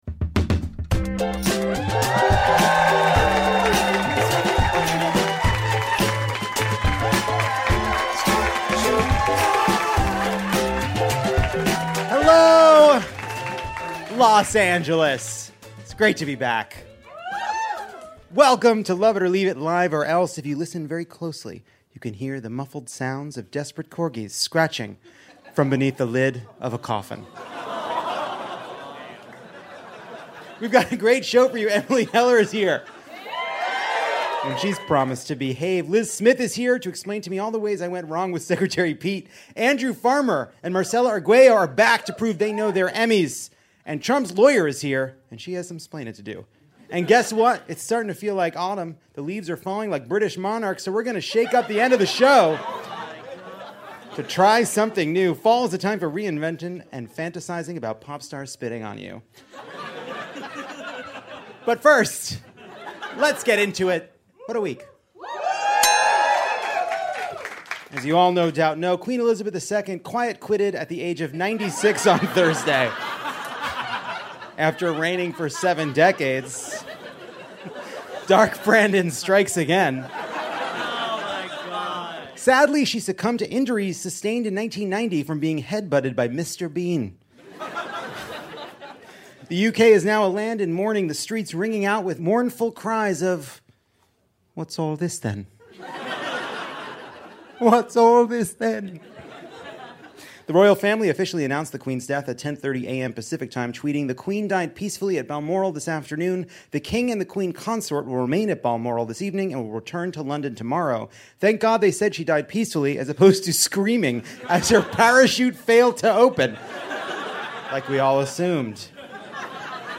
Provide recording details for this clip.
The show makes its triumphant return to Dynasty Typewriter.